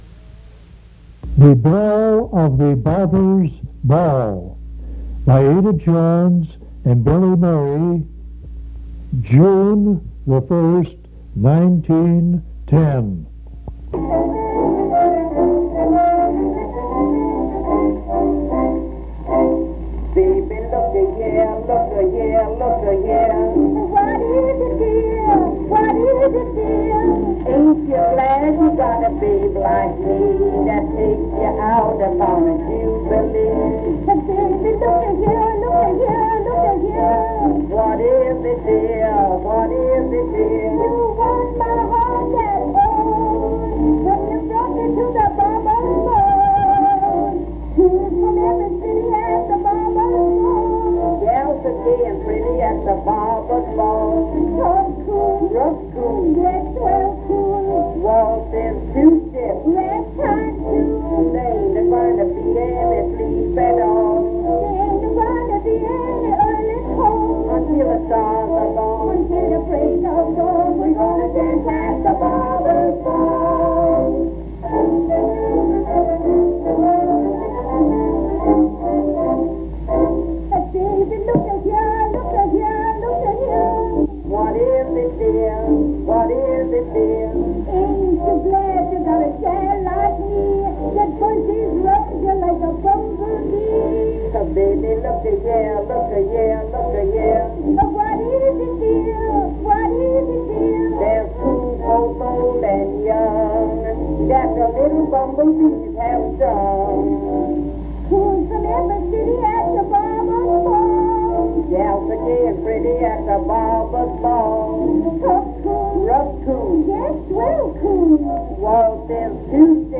Popular music